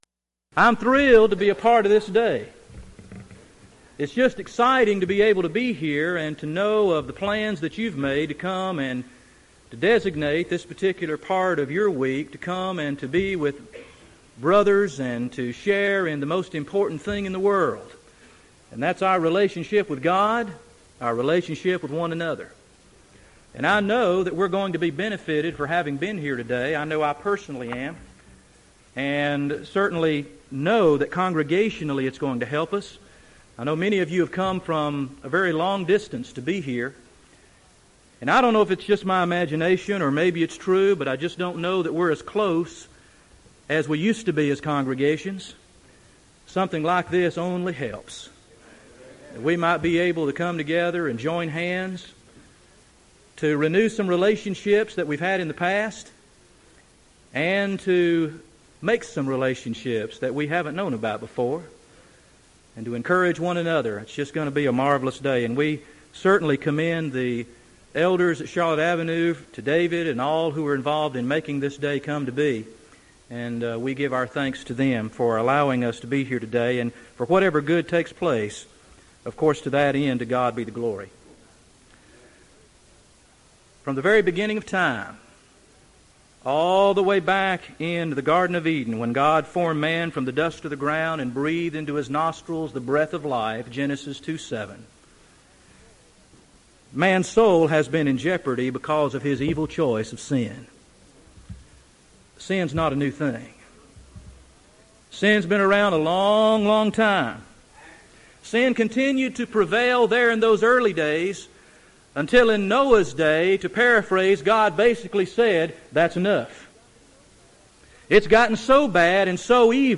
Event: 1999 Carolina Men's Fellowship
lecture